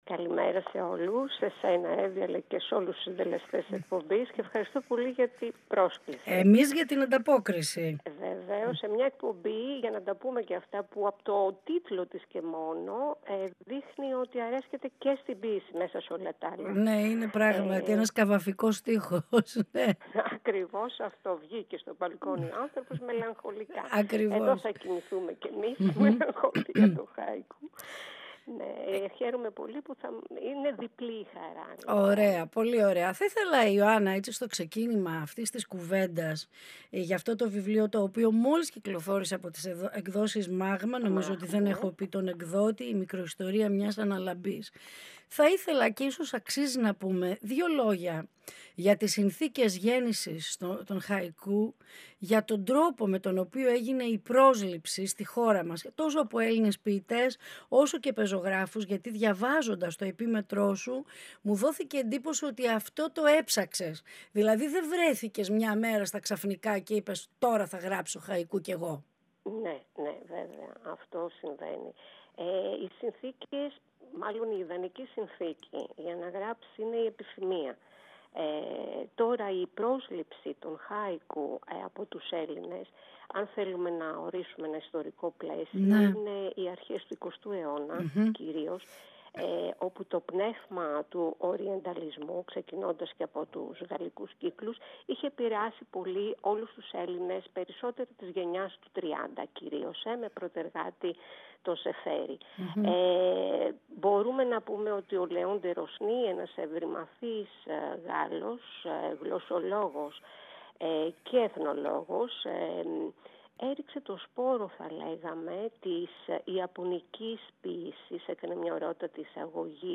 Ολιγη Κινηση του Δρομου και των Μαγαζιων Συνεντεύξεις